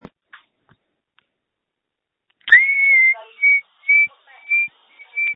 Screams from December 3, 2020
• When you call, we record you making sounds. Hopefully screaming.